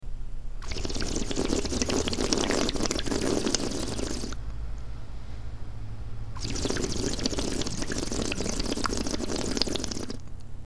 While the liquid is in the mouth, they suck in air. You'll hear a warbling kind of sound.
UPDATE: Looks like the video was removed from YouTube, so I've made a recording of the strange water sound.
Sound of water/warbling
baby-water.mp3